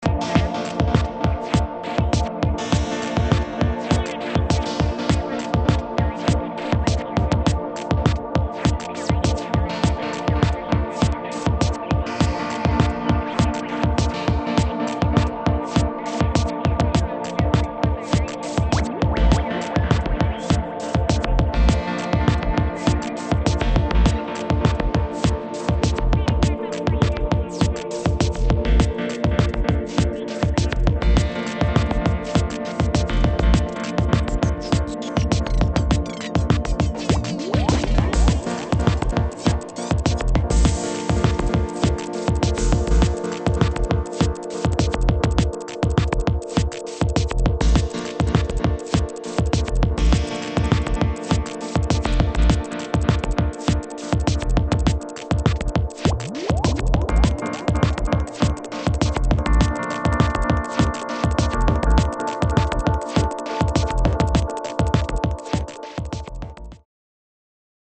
[ DUBSTEP / IDM ]